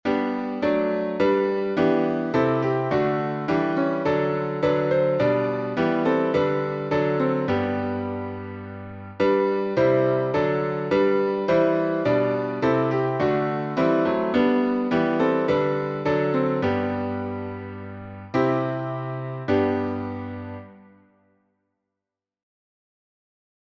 Key signature: G major (1 sharp) Time signature: 4/4